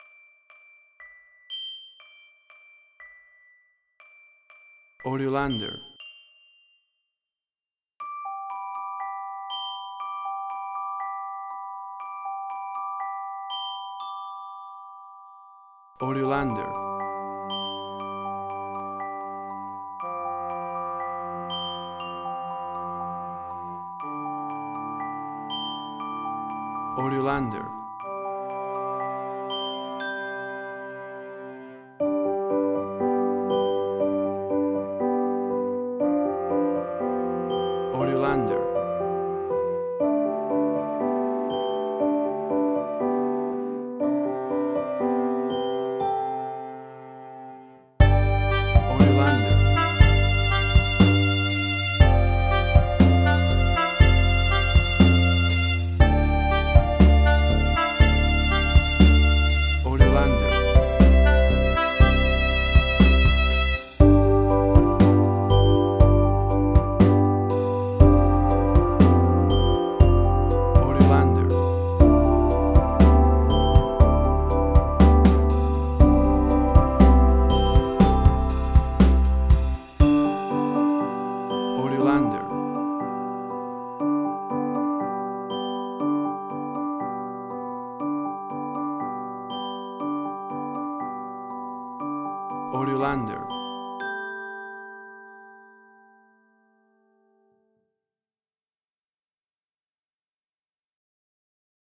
Indie Quirky
emotional music
WAV Sample Rate: 16-Bit stereo, 44.1 kHz
Tempo (BPM): 61